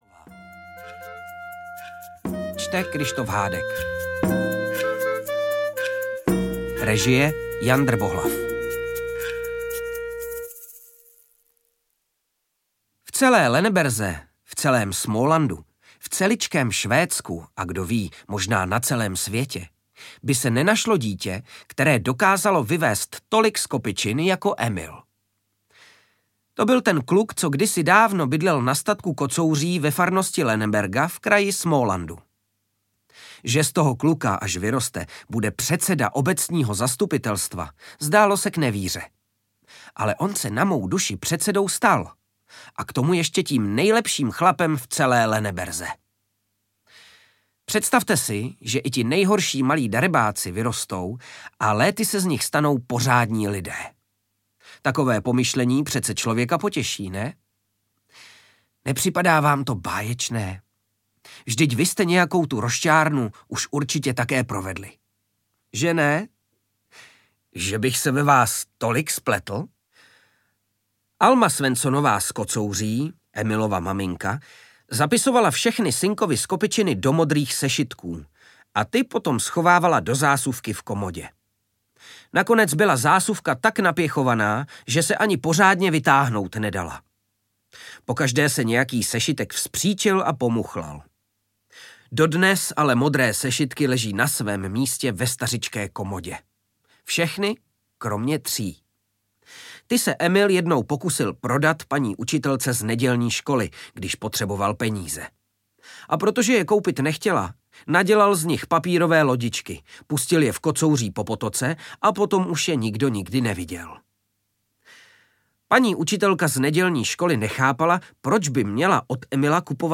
Emilovy další skopičiny audiokniha
Ukázka z knihy
• InterpretKryštof Hádek